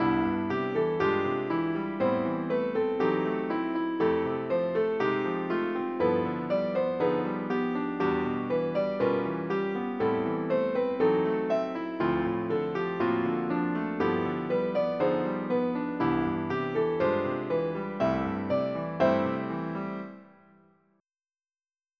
Reharmonized melody with jazz substitutions
Result: Jazz reharmonization with tritone subs, secondary dominants, and chromatic approach chords
piano_Reharmonize.wav